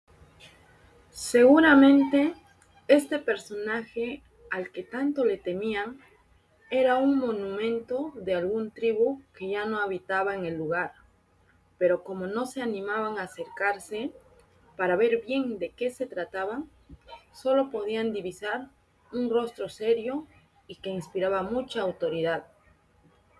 Audio libro (La ranitas y el tronco tallado)